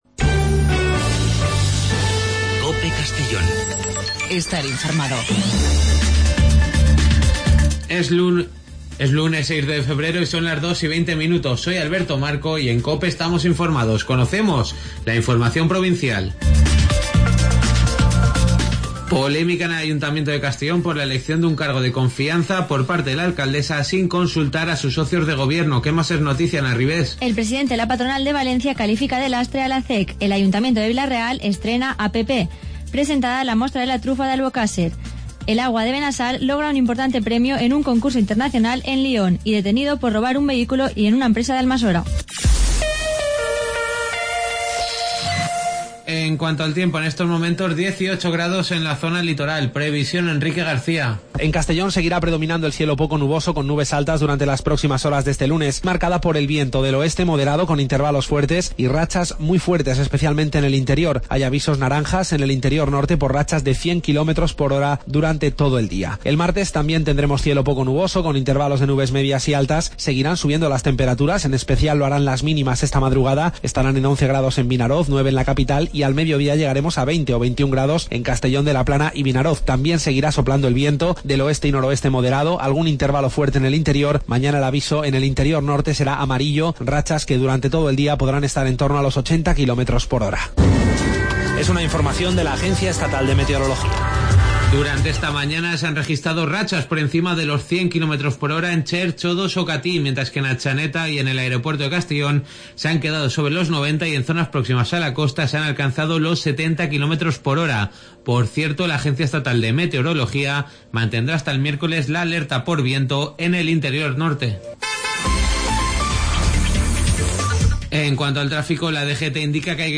AUDIO: Las noticias del día de 14:20 a 14:30 en Informativo Mediodía COPE en Castellón.